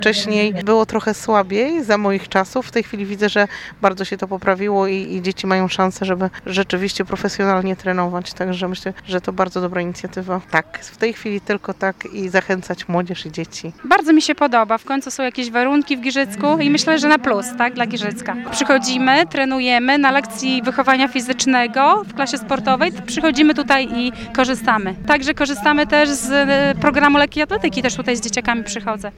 Najbardziej z nowej inwestycji cieszyli się rodzice i nauczyciele klas sportowych.
rodzic-i-nauczyciel.mp3